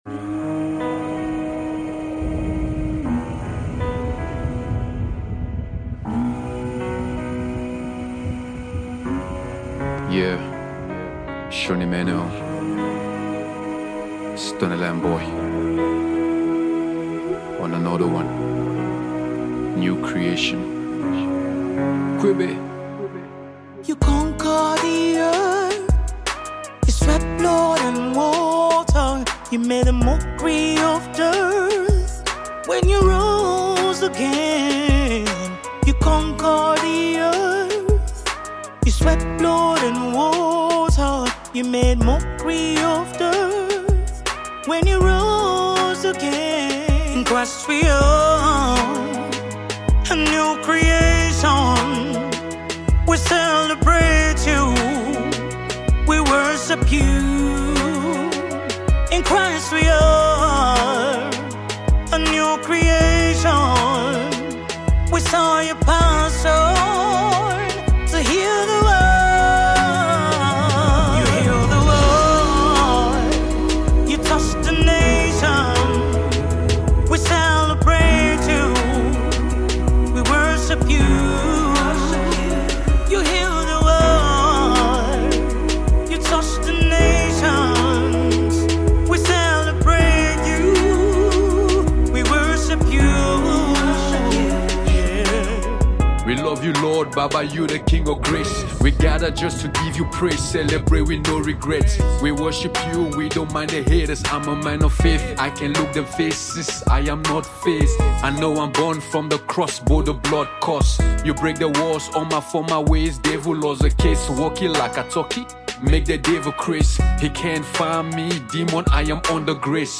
contemporary gospel classic